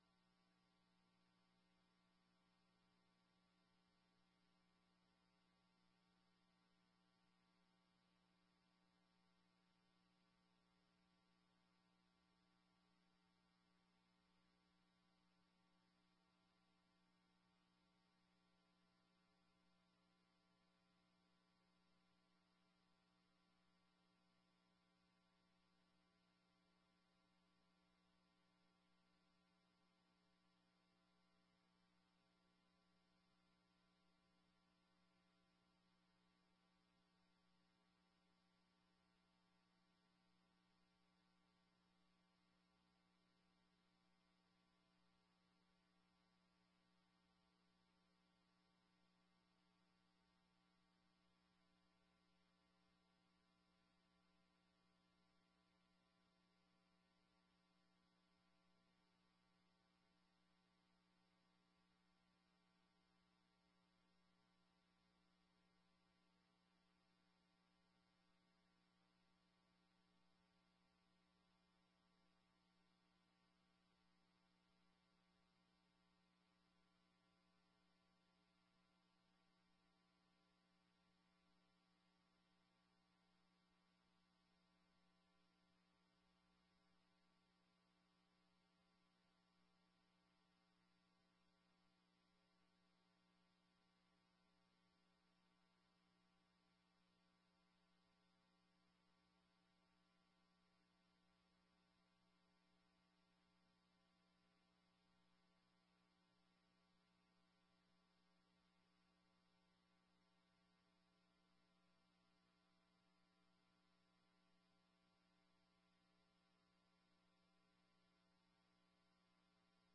18ª Sessão Ordinária de 2019 — Câmara Municipal de Garça